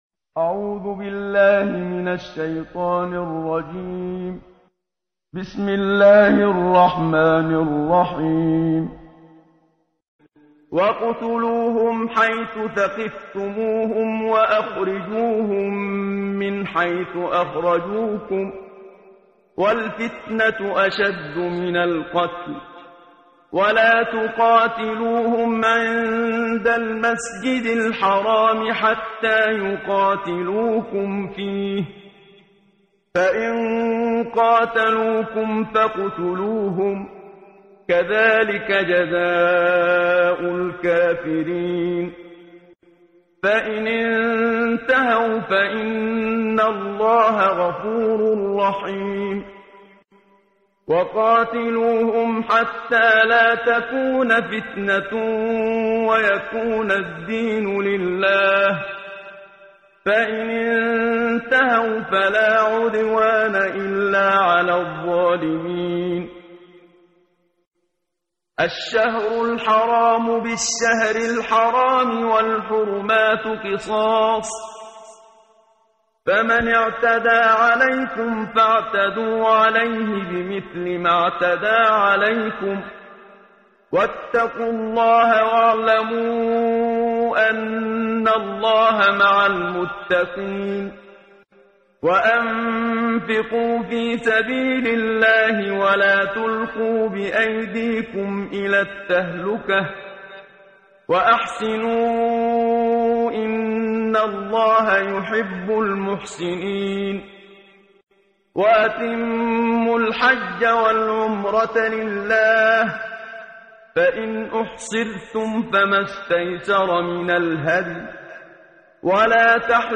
قرائت قرآن کریم ،امروز، صفحه 30، سوره مبارکه بقره آیات 191تا 196 با صدای استاد صدیق منشاوی.